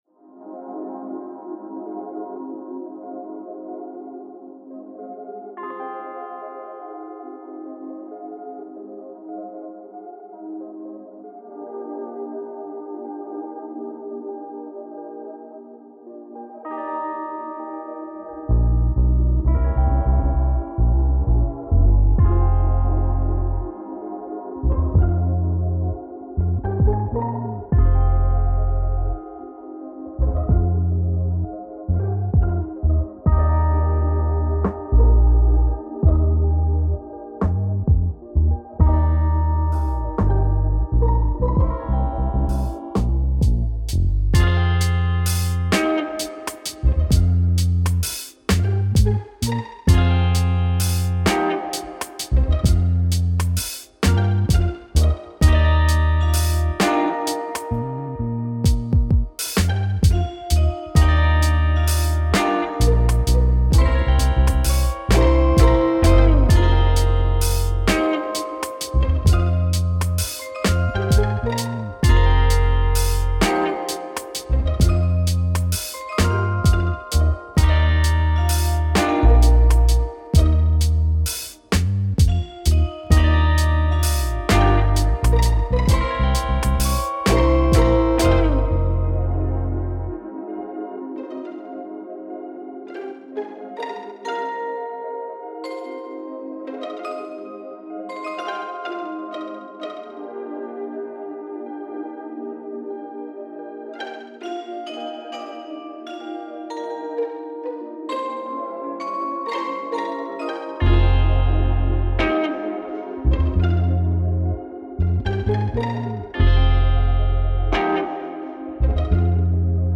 A spacious and chilling beat for spilling your guts over